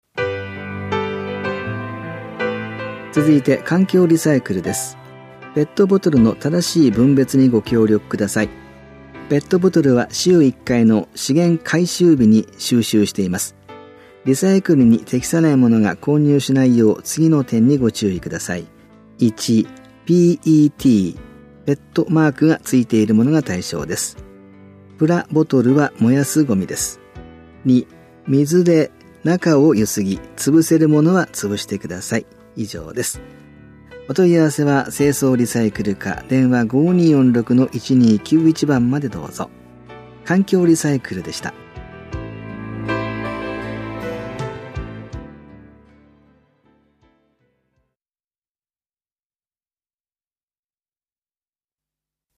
広報「たいとう」平成30年7月5日号の音声読み上げデータです。